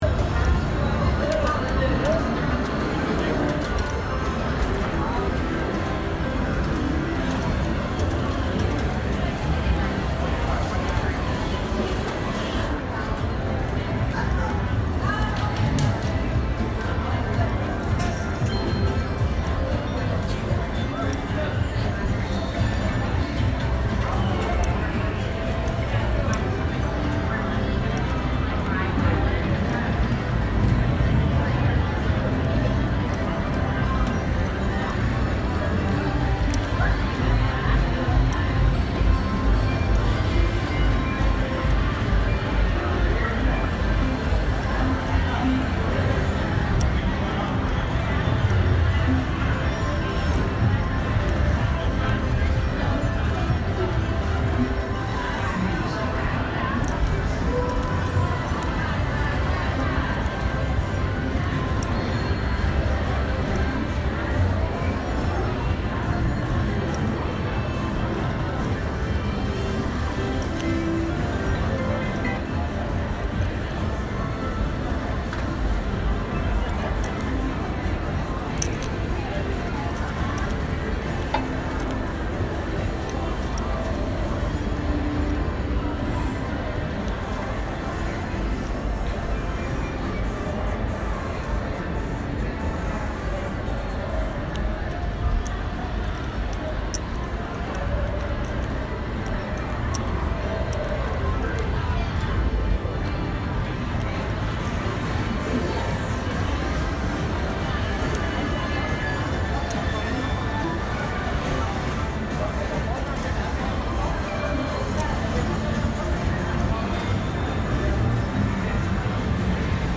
On the casino floor at Harrah's
Sounds recorded at Harrah's Casino in downtown New Orleans, Louisiana. These are some of the typical sounds you'd expect to hear in a casino in this region.